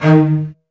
CELLOS.FN3-L.wav